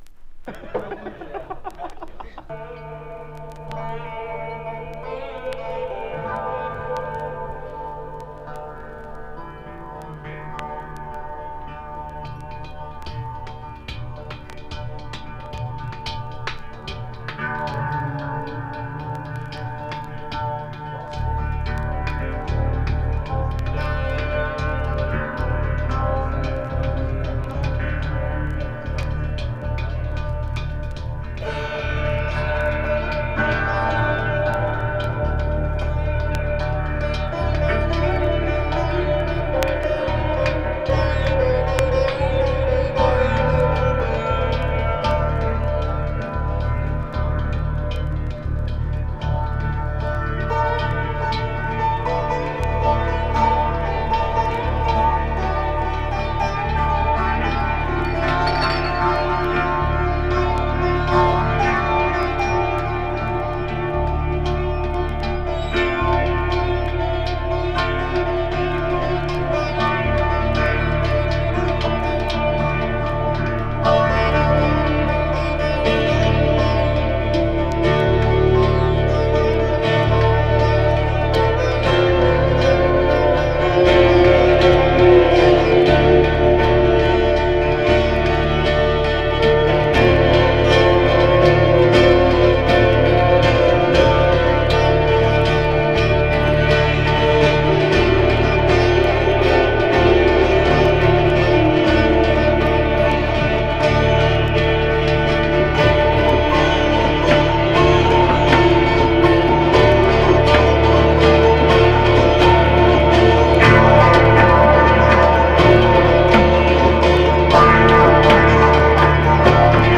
エンテオゲンの効果を聖体物質として再現することを目的としたモンスター・サイケデリック・トリップ。